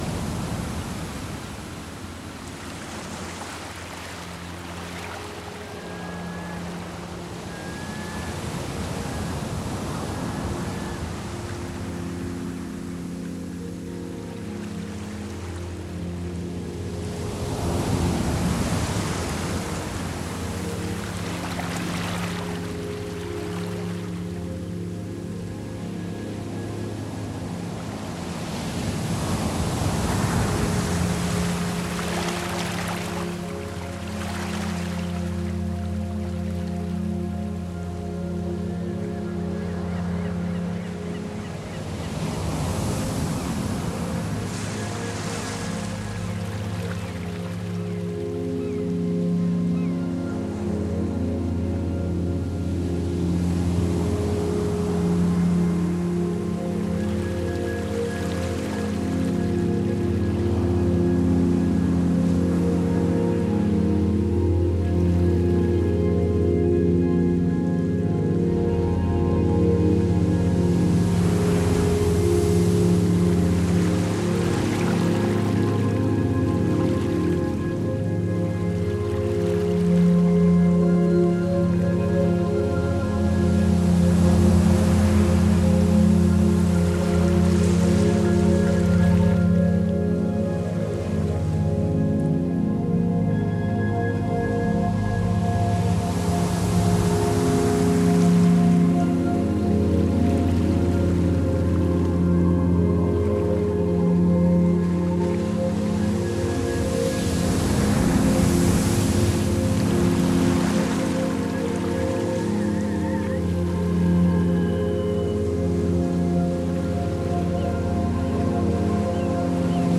New age Медитативная музыка Нью эйдж